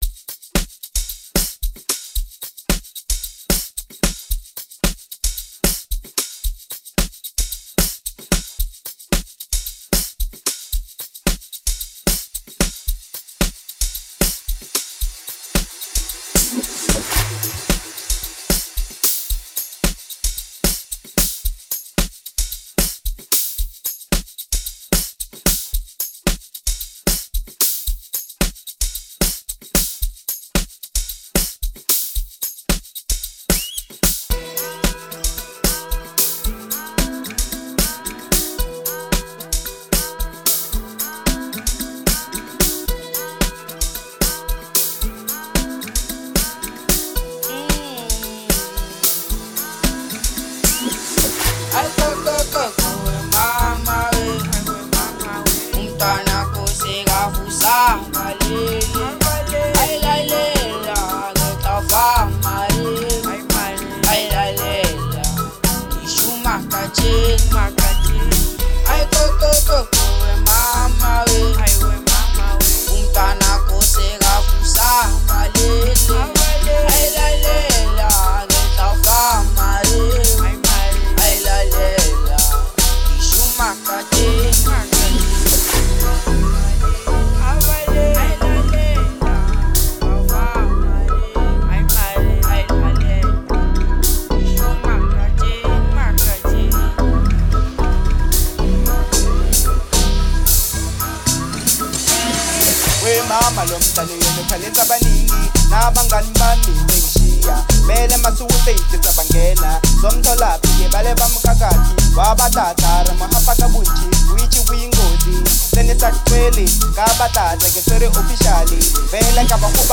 05:18 Genre : Amapiano Size